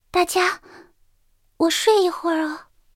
三号中破修理语音.OGG